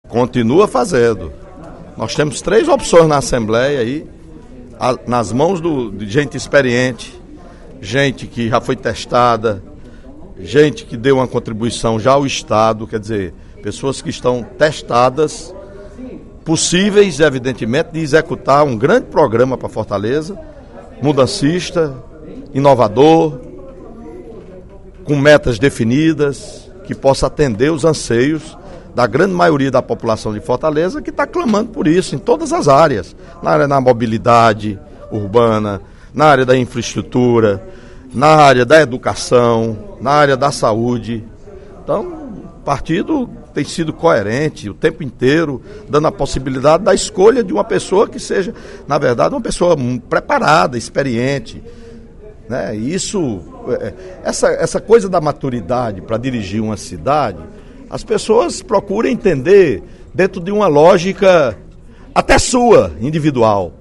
O deputado Welington Landim (PSB), líder do bloco PSB-PT, ao comentar a sucessão da Prefeitura de Fortaleza, na sessão plenária desta quarta-feira (13/06), disse que o governador Cid Gomes está sendo coerente e será até o último momento. Segundo o parlamentar, Cid sempre colocou muito claramente no interior da sigla socialista que quer a manutenção da aliança PT/PSB.